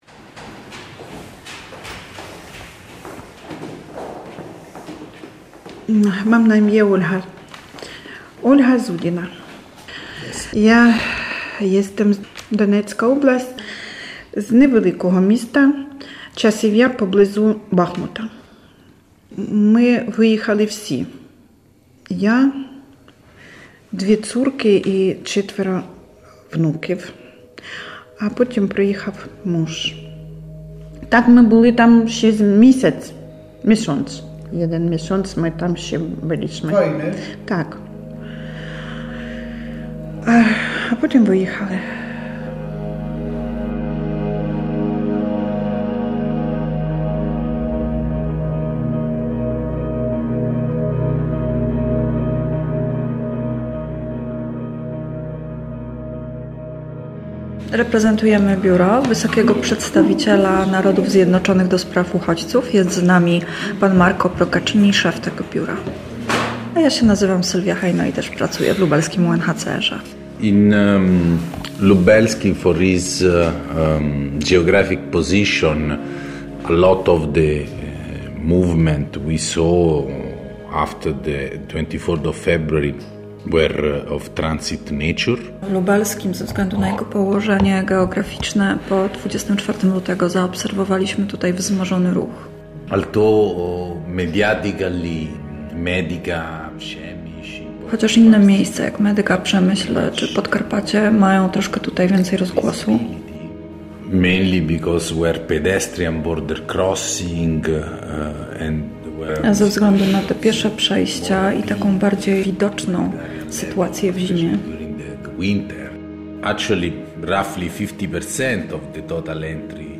W przedświąteczny poniedziałek przypomnimy reportaż o Władysławie Grochowskim, prezesie firmy Arche, filantropie. Odbierając prestiżową nagrodę przyznaną przez ONZ za pomoc uchodźcom Władysław Grochowski powiedział, że ma wątpliwości, czy jednym celem prowadzania firmy jest zarabianie pieniędzy.